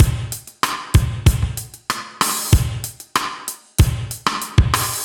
Index of /musicradar/80s-heat-samples/95bpm
AM_GateDrums_95-02.wav